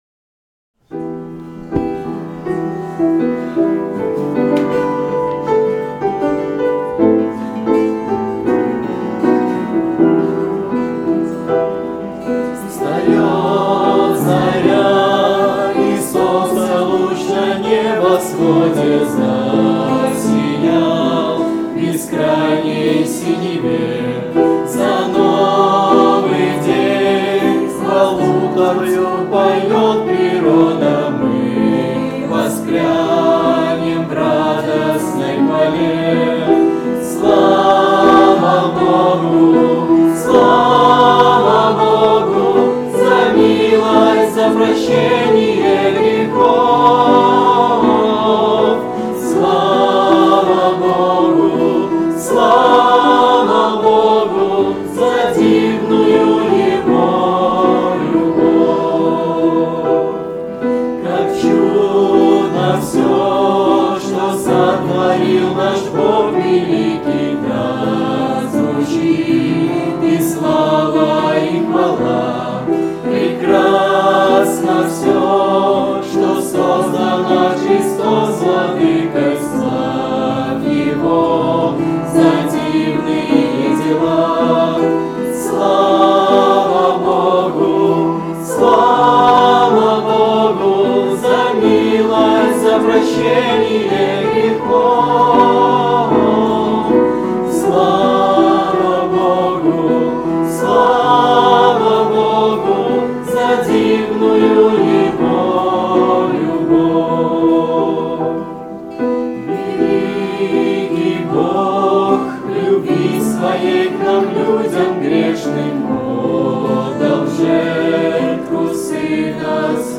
22-10-17 / Встаёт заря (Молодёжное прославление)